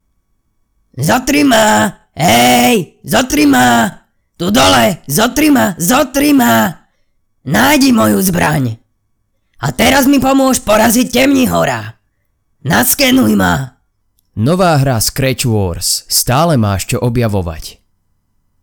Profesinálny mužský VOICEOVER v slovenskom jazyku